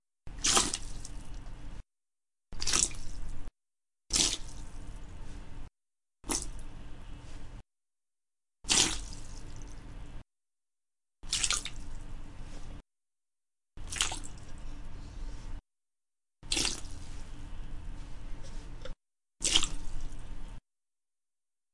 水 " 飞溅的水
描述：接近透视的水溅与缩放h6记录